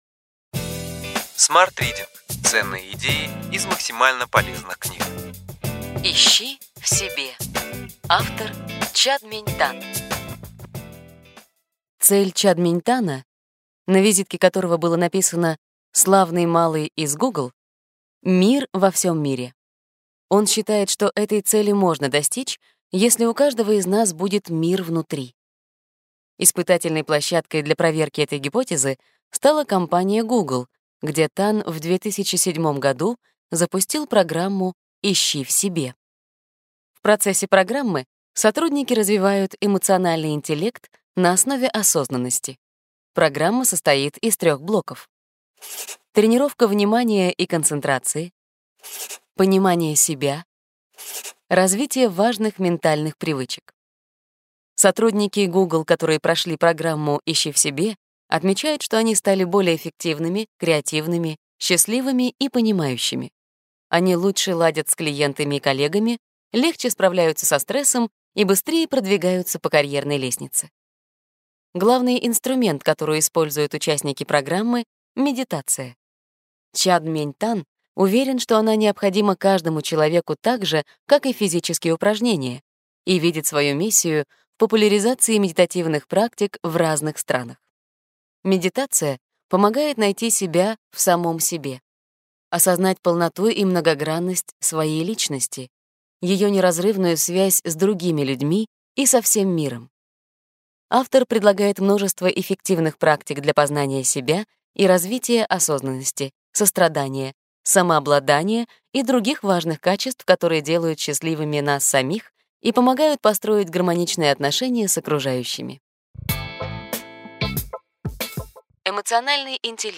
Аудиокнига Ключевые идеи книги: Ищи в себе: неожиданный способ достижения успеха, счастья и мира. Чад-Мень Тан | Библиотека аудиокниг